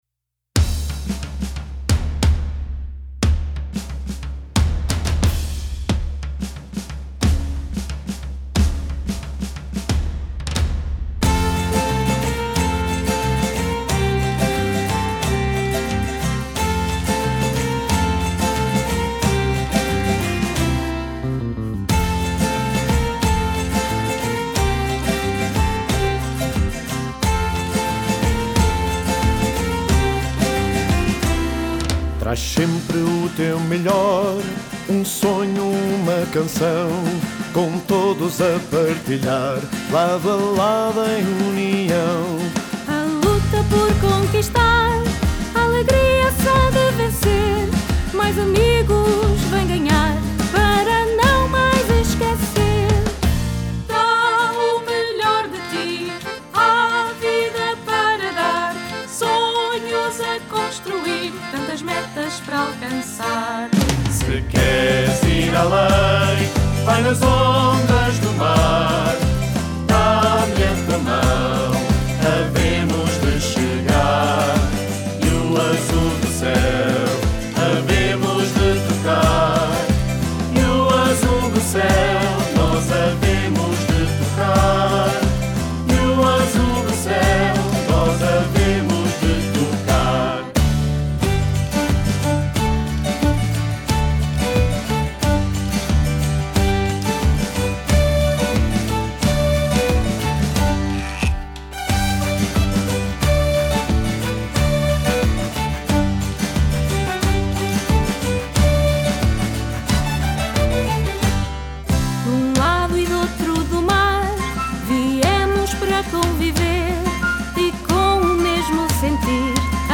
Jogos Nacionais Salesianos já têm hino
o hino da 29.ª edição é uma homenagem à Ilha da Madeira, à cultura e à música tradicional do arquipélago.